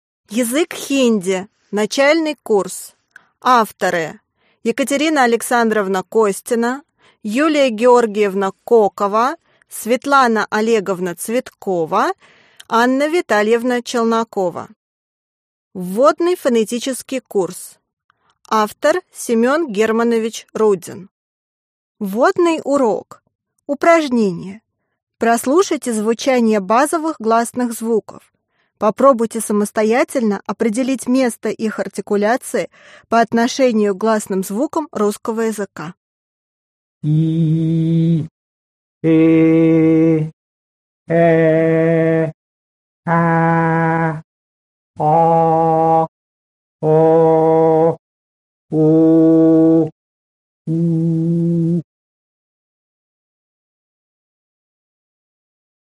Аудиокнига Хинди. Начальный курс | Библиотека аудиокниг